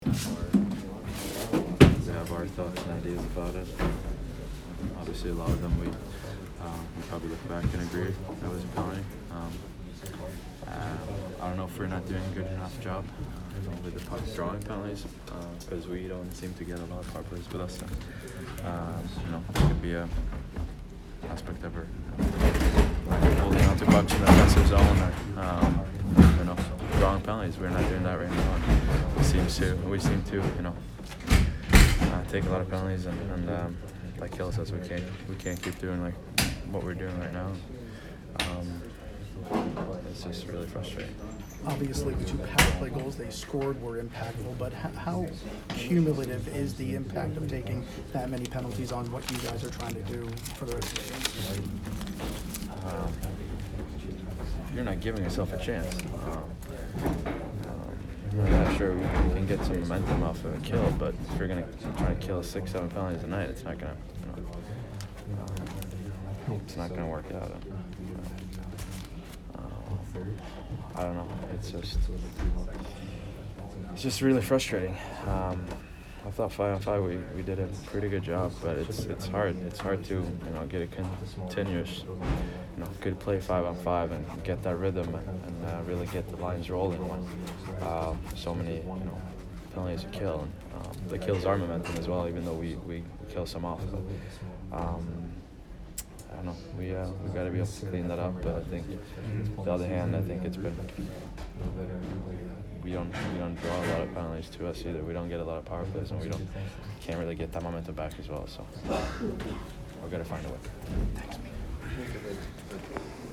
Mike Zibanejad post-game 12/10